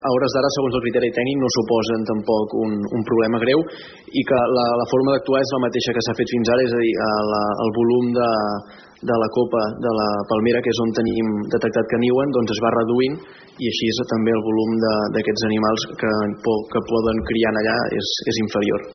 Al ple ordinari d’aquest mes d’octubre, la regidora de Palafolls en Comú, Clara Hidalga, va preguntar al govern municipal sobre l’existència d’una colònia d’aquestes aus al nostre municipi.